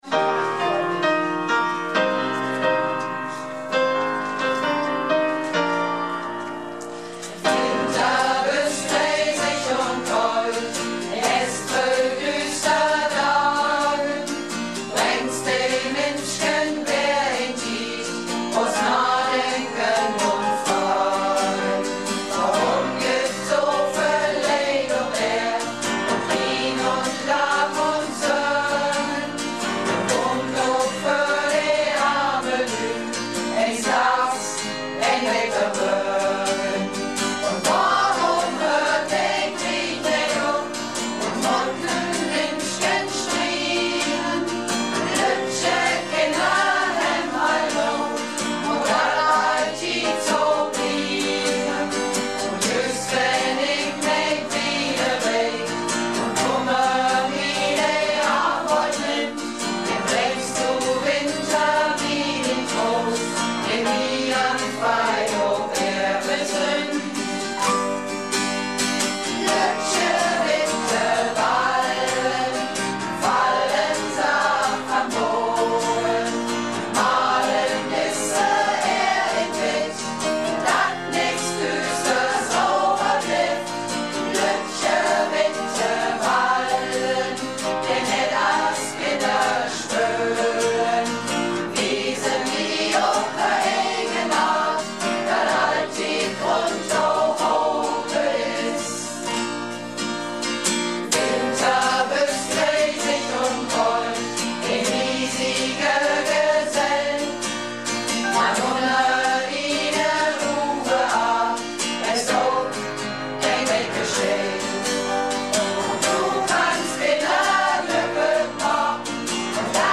(Gesamtprobe)